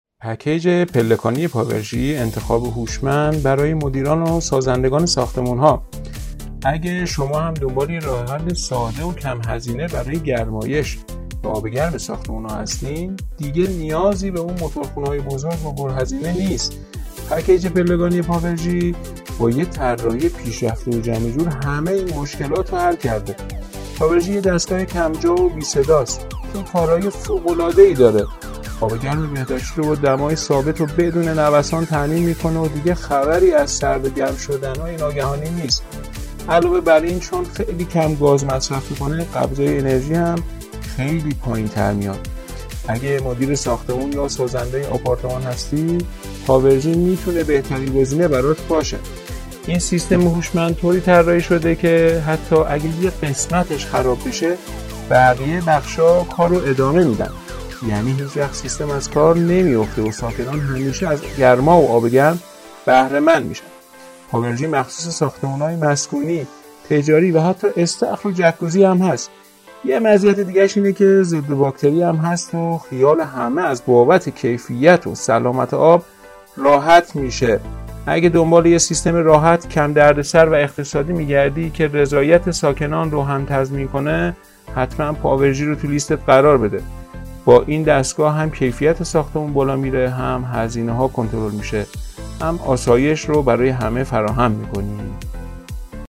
معرفی صوتی ویژگی های پکیج پاورژی